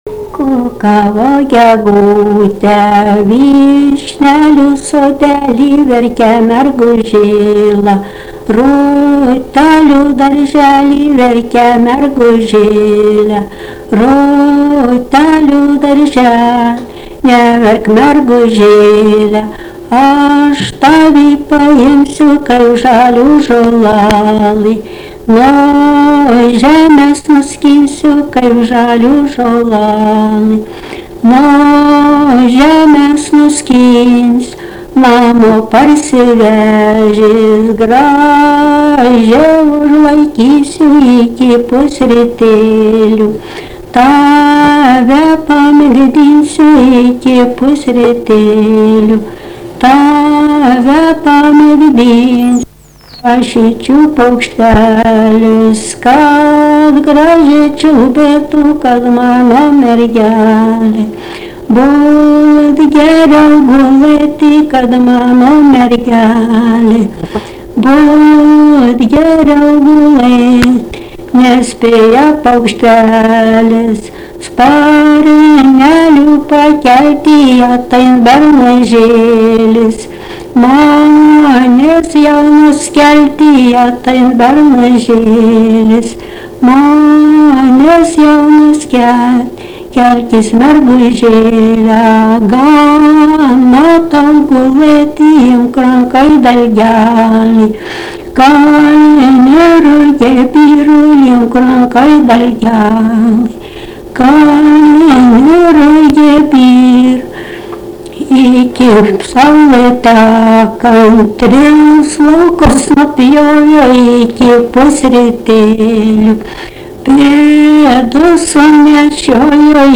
daina
Ryžiškė
vokalinis